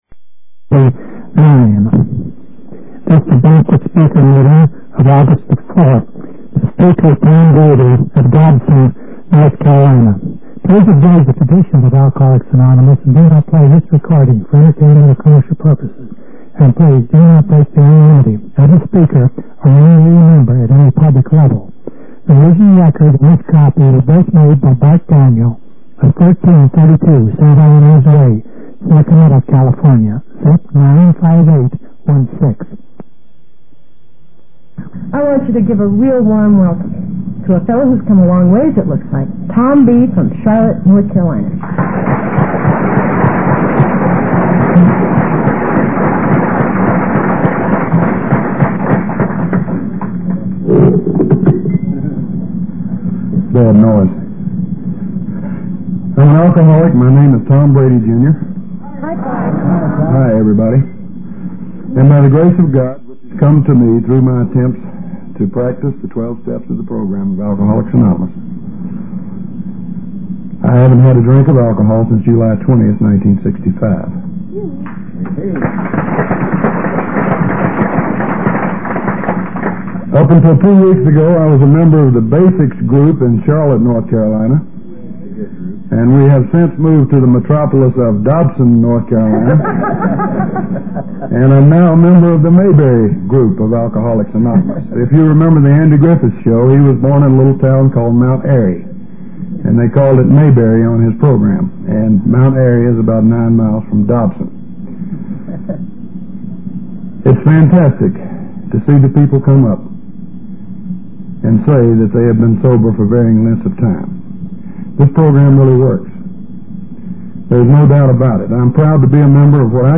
Popular AA Speakers